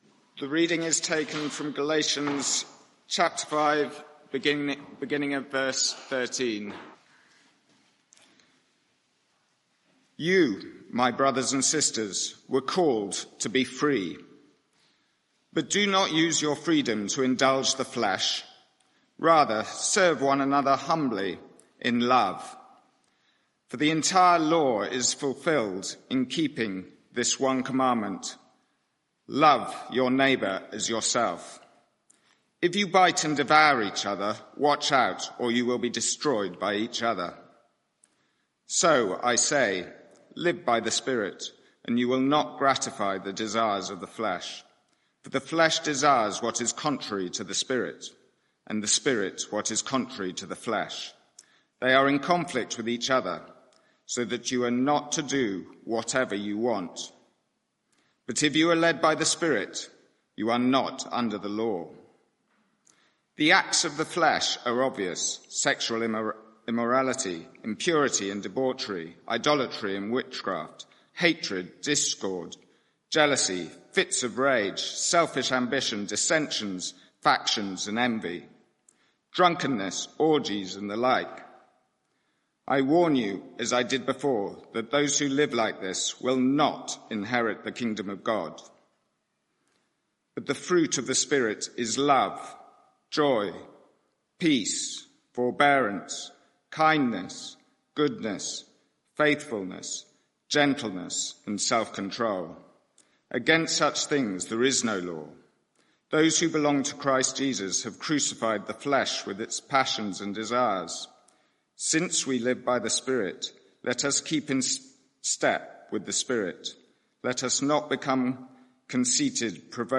Media for 9:15am Service on Sun 19th May 2024 09:15 Speaker
Sermon (audio)